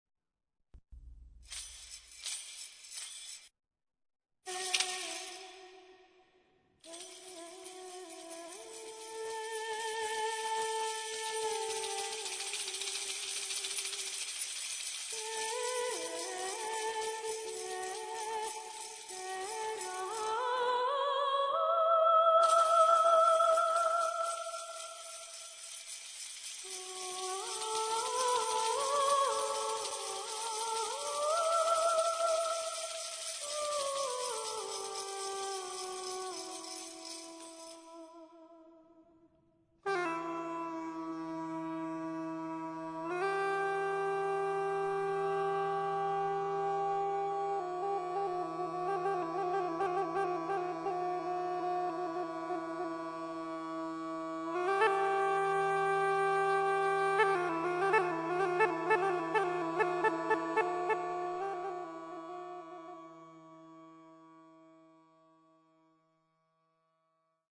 L'audio ne propone una ricostruzione moderna: audio/mpeg